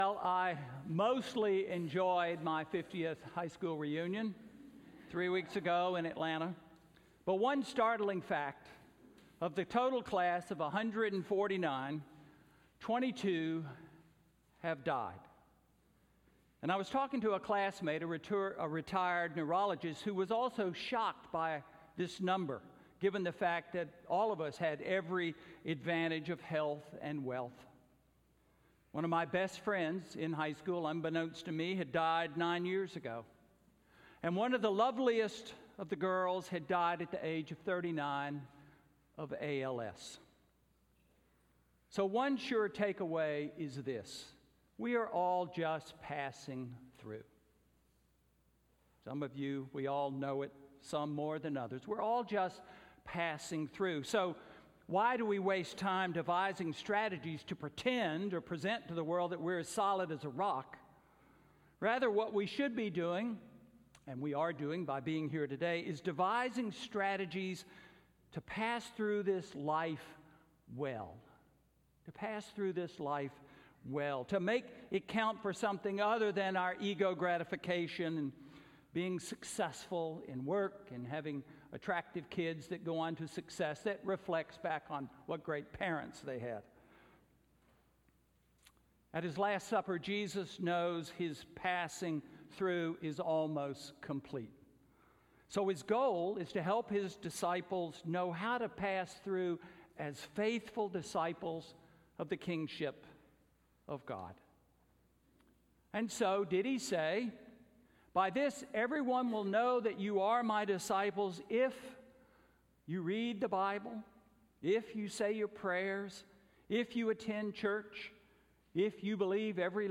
Sermon: What are we known for? May 19, 2019